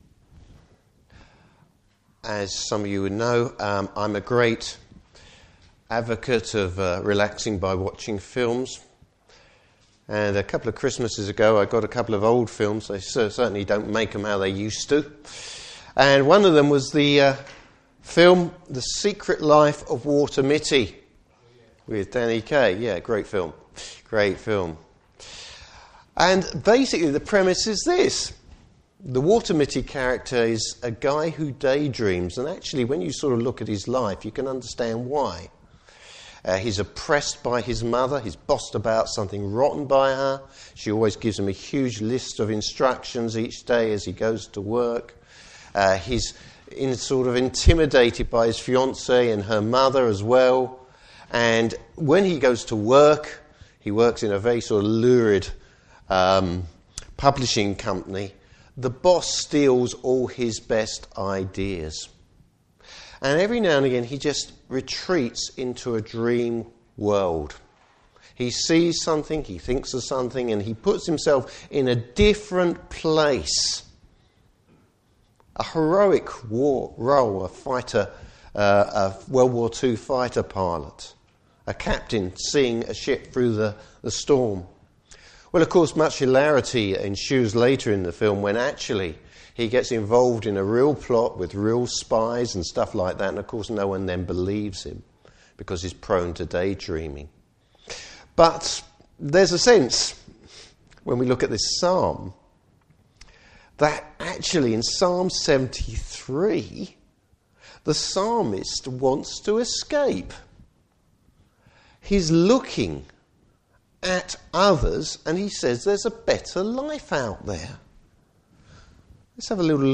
Service Type: Morning Service Bible Text: Psalm 73.